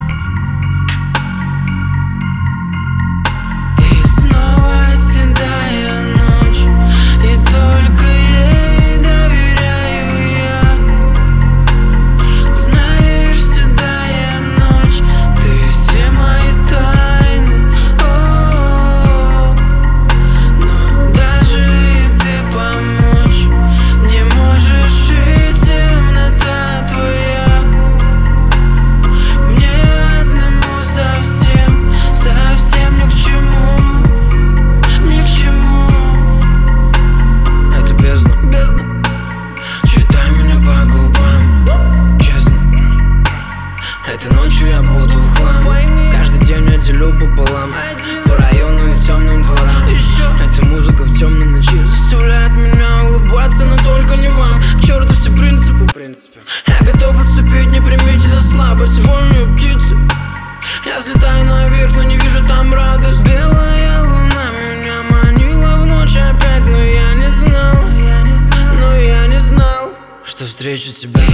• Качество: 320, Stereo
грустные
спокойные
Trap
Cover
тиканье часов